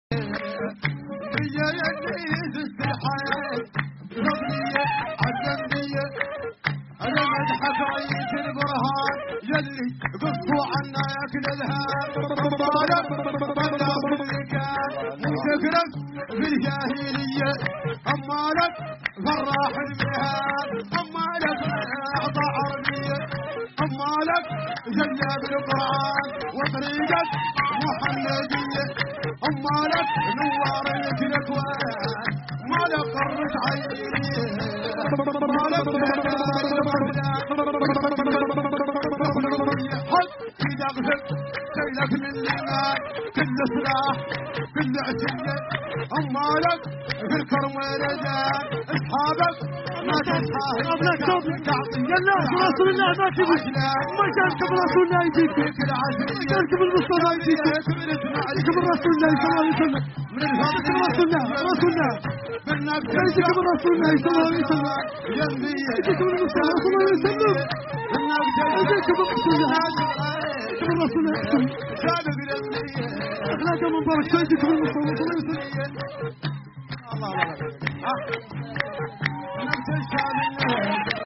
يُؤدَّى المدح بشكل جماعي، حيث تُوزَّع الأدوار بين قارعي الطبول “الشَدَّادَهْ”، وأصحاب الأداء الصوتي “المَدَّاحَة” و”الرَدَّادَة”، وذلك بالتوازي مع العزف على الآلات الموسيقية التقليدية إن وُجدت.
المديح-النبوي-الشعبي-في-موريتانيا.mp3